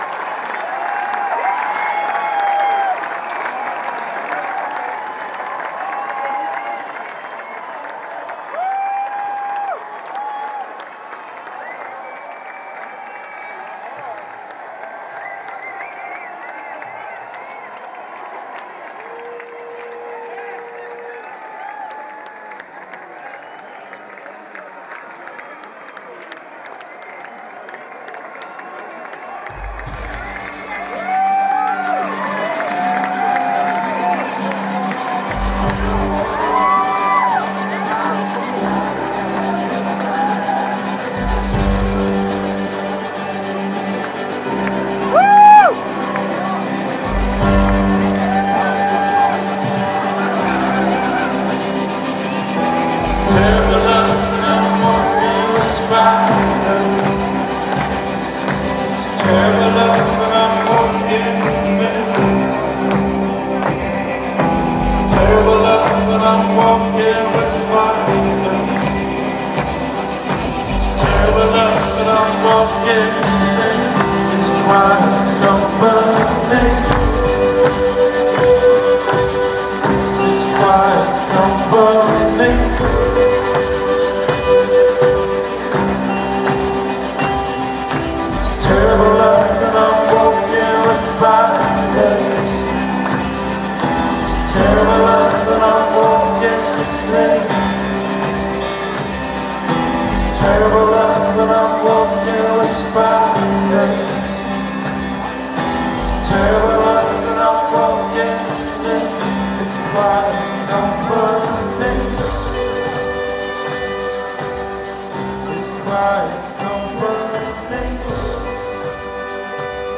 This is a recording from their recent show at Radio City.